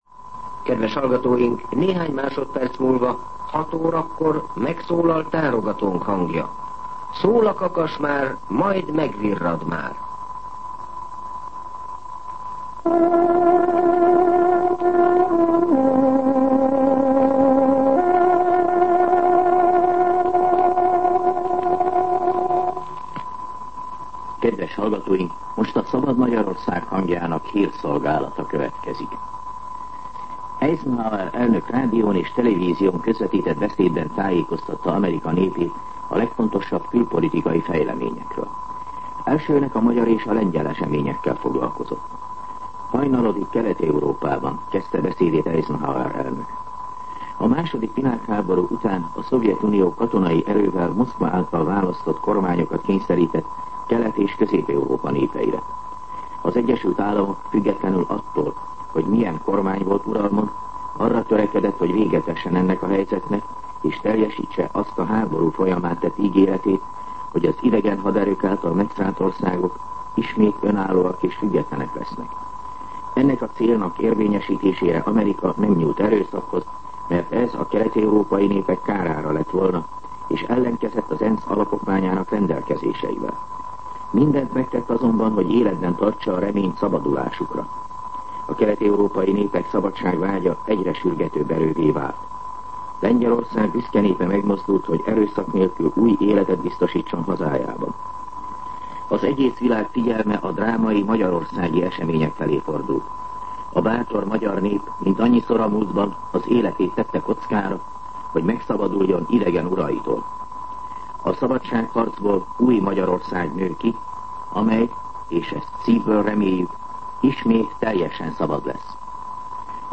06:00 óra. Hírszolgálat